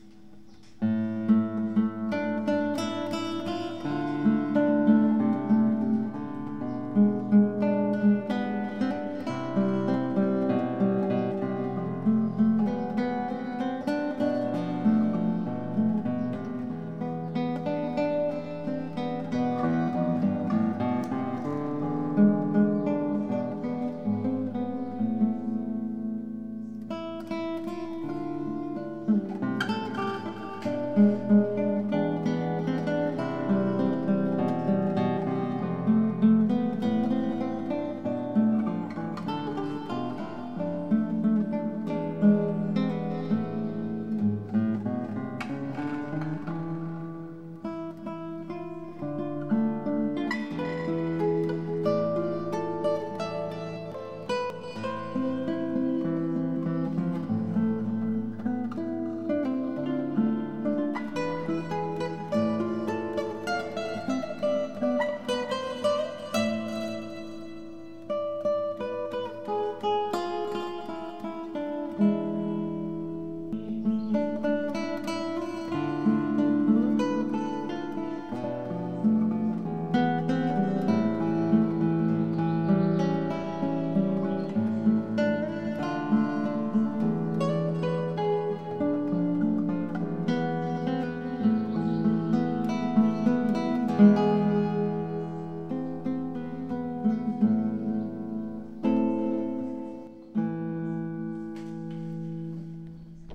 - Guitare Classique
Pleine de tendresse ( c'est le cas de le dire )